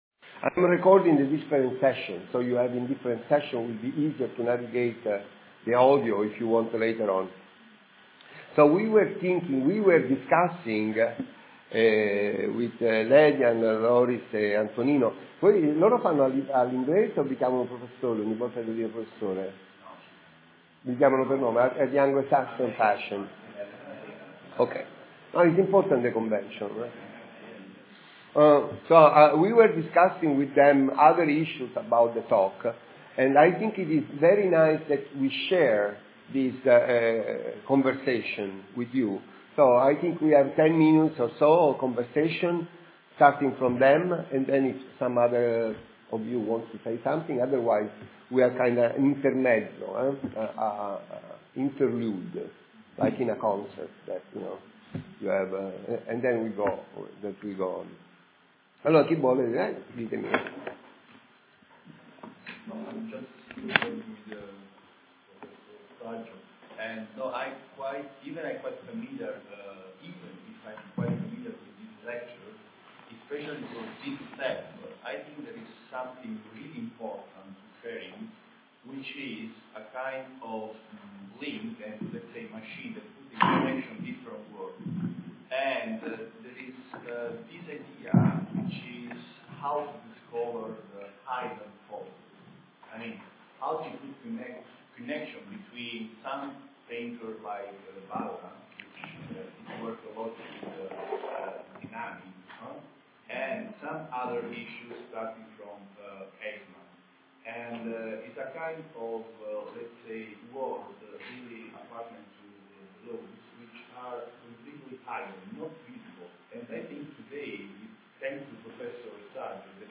Home Publications Lectures The ITRevolution